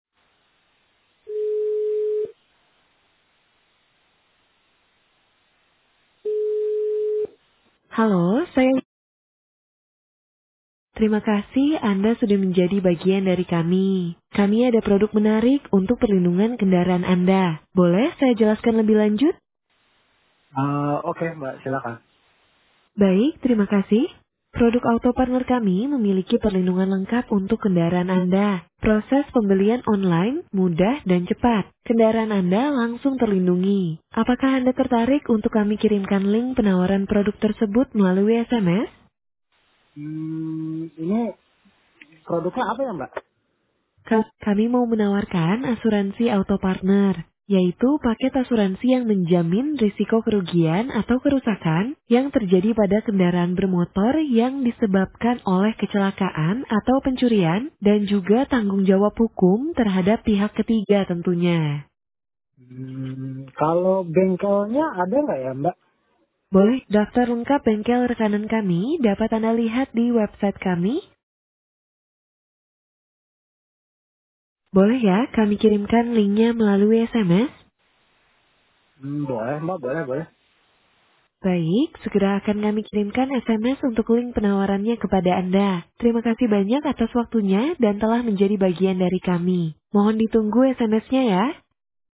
Here are some sample recordings for Smart AI Voice Robot conversations with customers with various contact center functions.
In Indonesia, insurance has also started to develop, here is an example of the voice of Smart AI Voice in offering insurance.